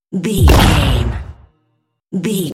Dramatic hit explosion
Sound Effects
heavy
intense
dark
aggressive
the trailer effect